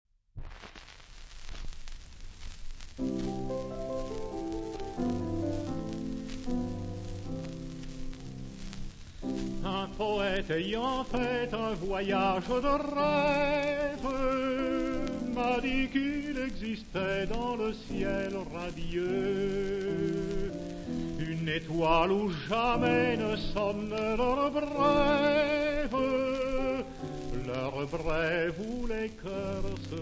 • lieder
• pianoforte